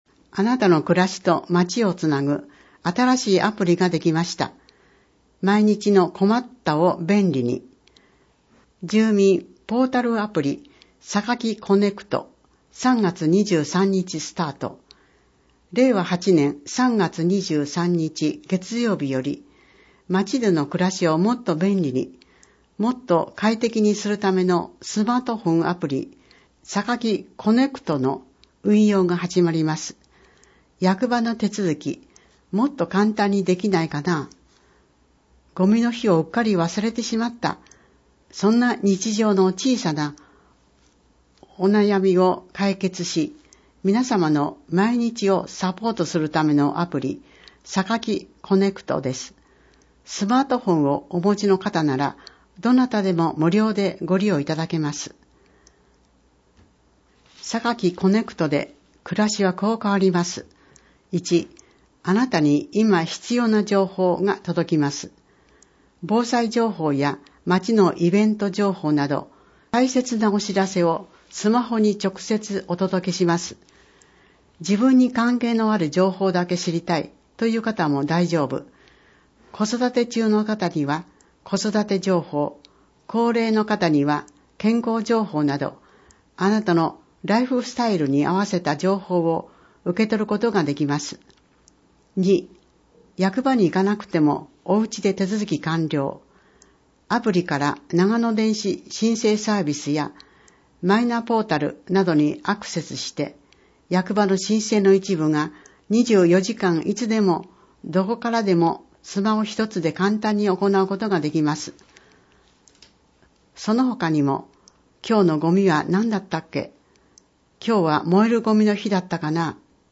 また、音訳ボランティアサークルおとわの会のみなさんによる広報の音訳版のダウンロードもご利用ください。